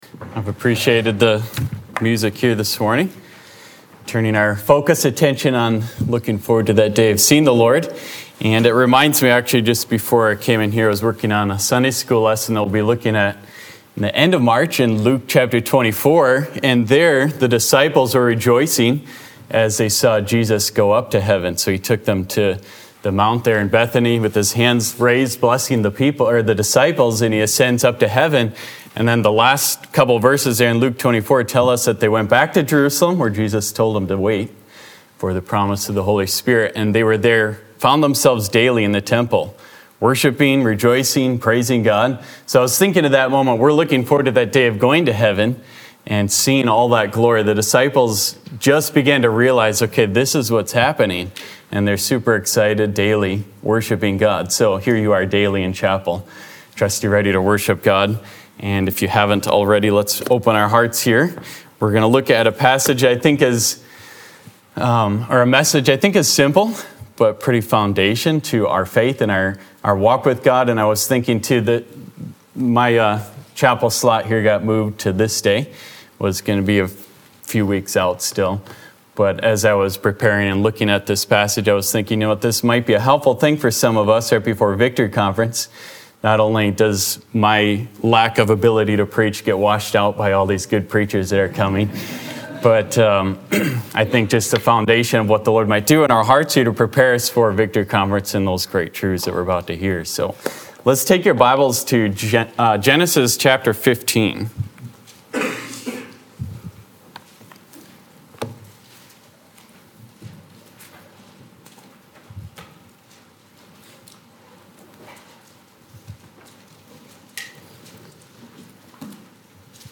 2024-2025 BCM Chapel